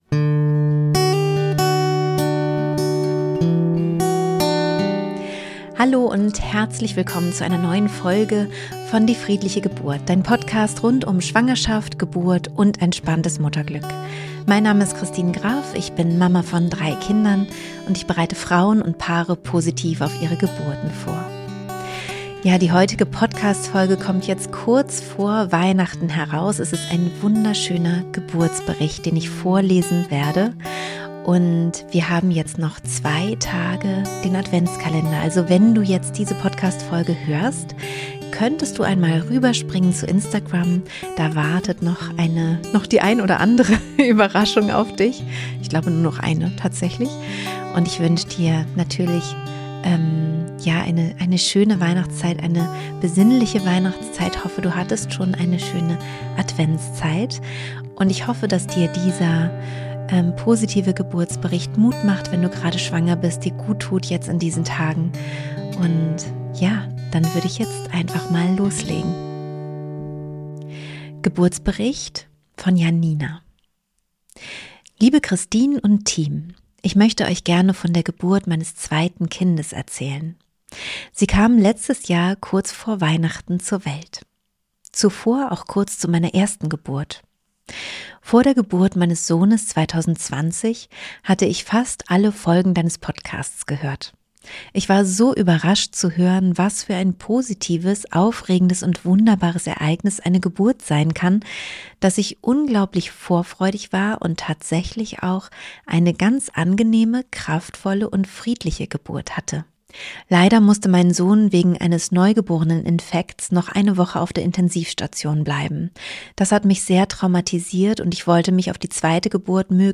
So kurz vor dem Weihnachtsfest bekommst du heute eine Weihnachtsgeschichte in Form eines Geburtsberichtes, den ich dir vorlesen darf.